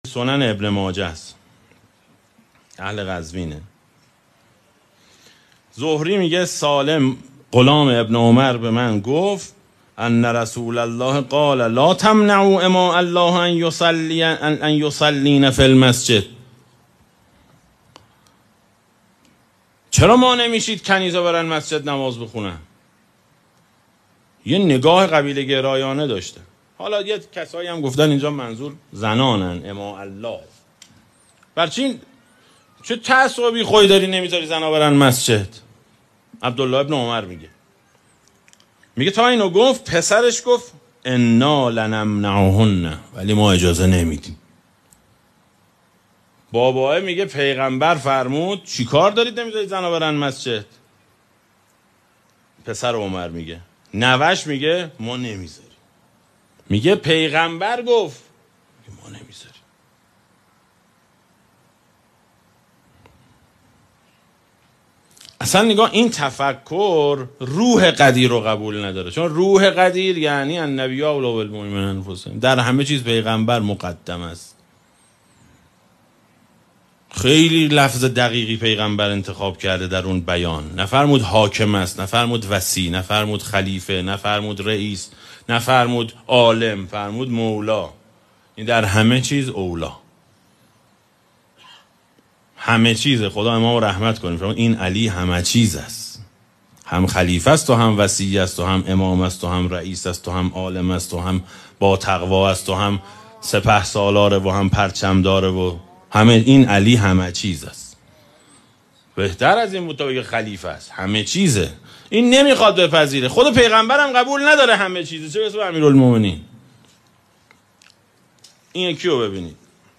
برگرفته از جلسه پنجم مباحث «کدام حسین؛ کدام کربلا»